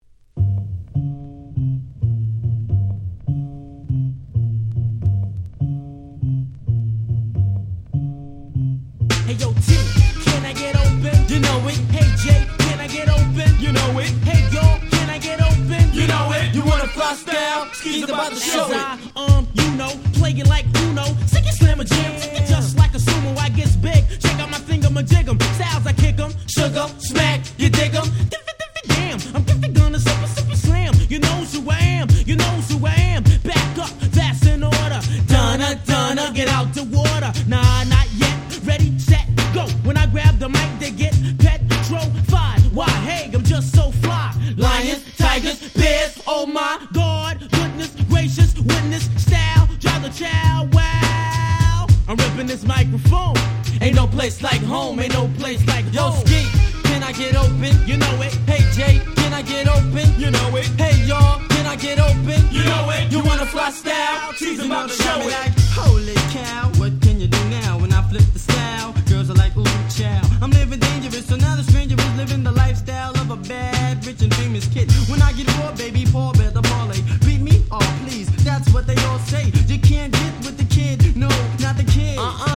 93' Very Nice Hip Hop !!
90's New School ニュースクール ジェイジー Boom Bap ブーンバップ